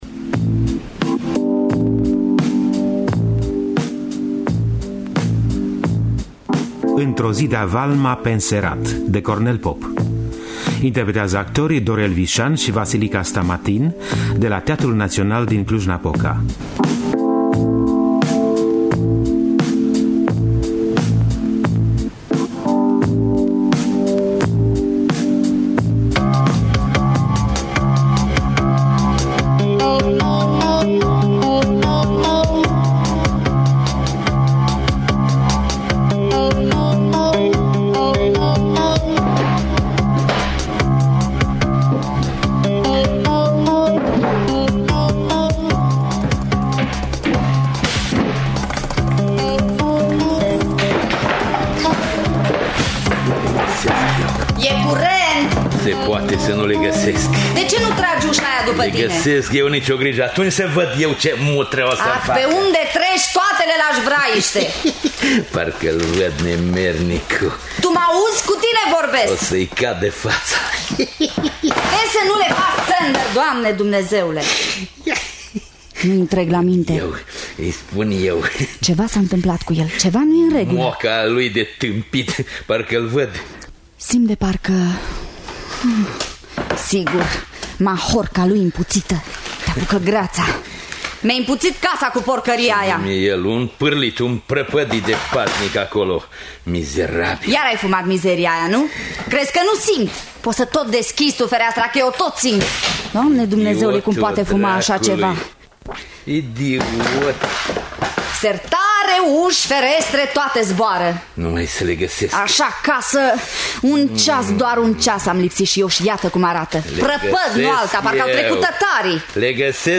Într-o zi, de-a valma, pe înserat de Cornel Popa – Teatru Radiofonic Online